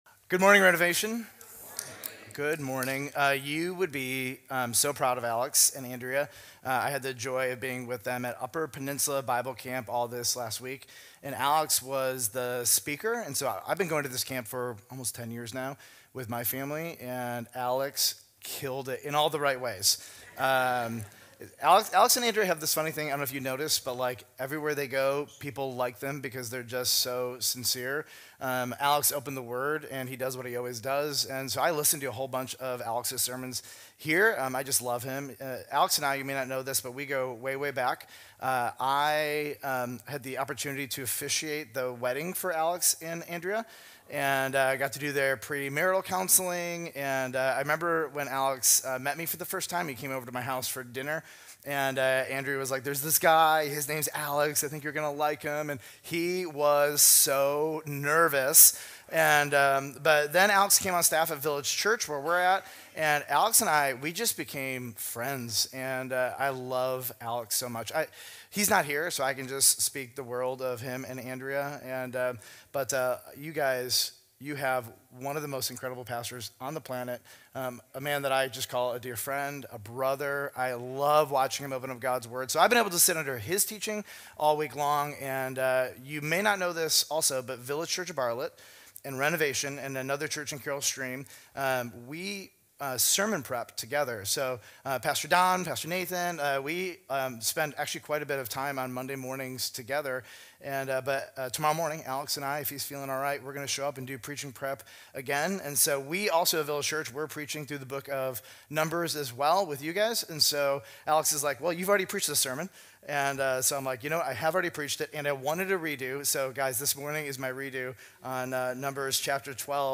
This message from the "Wanderings and Warnings" series unpacks the story of Miriam and Aaron’s jealousy toward Moses in Numbers 12. What starts as a complaint about Moses' wife is exposed as a deeper rebellion against God's chosen leadership. The sermon challenges the spirit of jealousy, gossip, and rebellion, especially when aimed at those in authority, and highlights how meekness—exemplified by Moses—is strength under control.